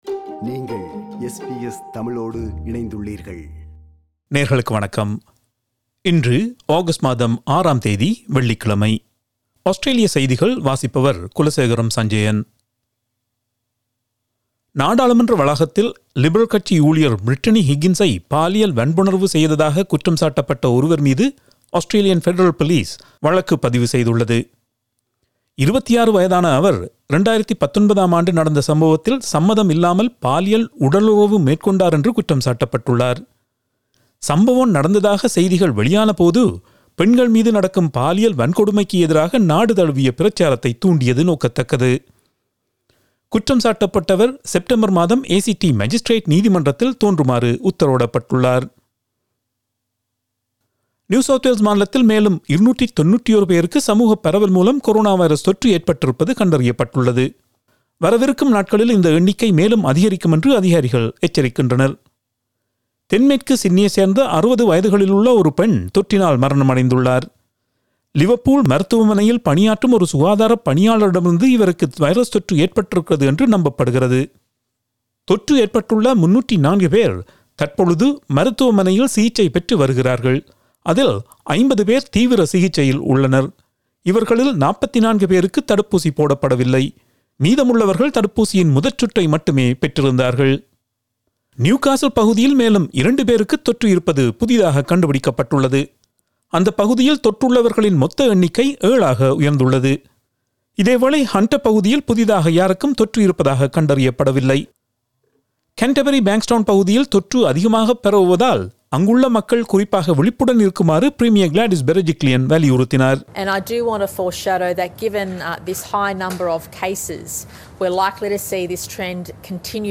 Australian news bulletin for Friday 06 August 2021.